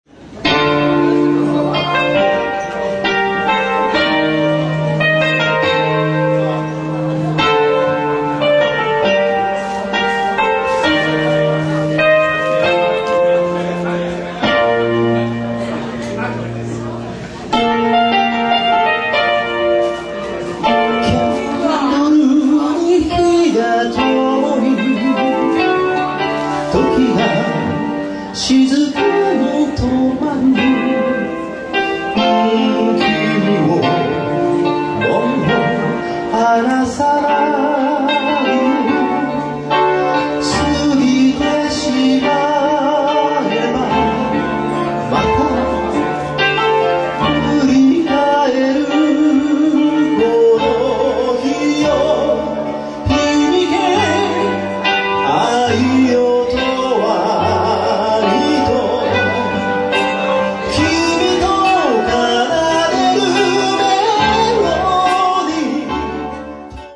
今回の選曲は基本的にポップでコンパクトな曲という基準で行ったので、練習は大変でしたが、それなりに楽しめるものになったと思います。
vocal
guitar
keybords,chorus
bass
drums